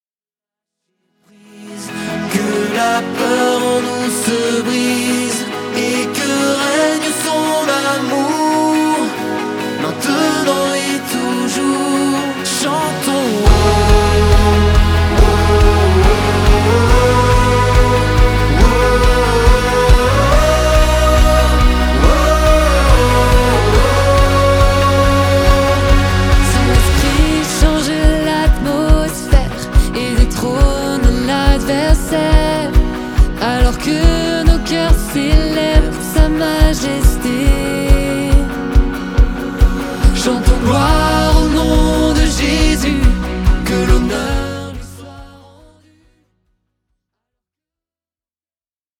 pop louange